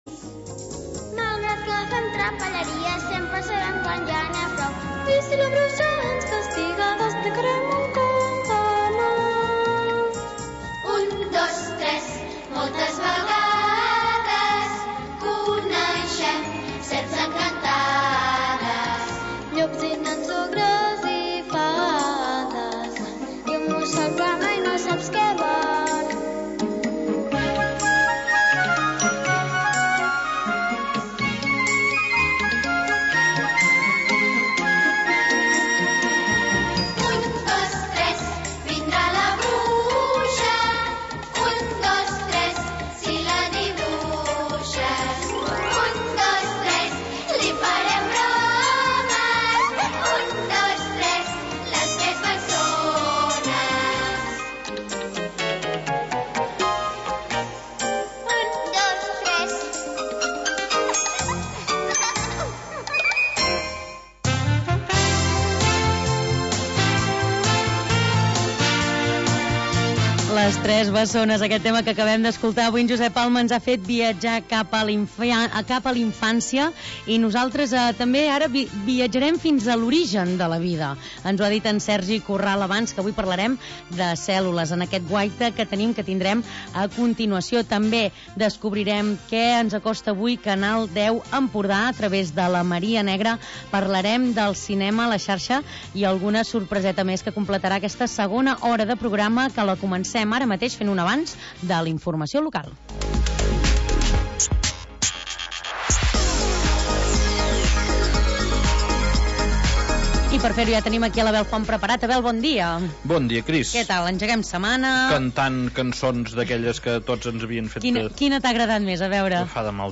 Magazín local d'entreteniment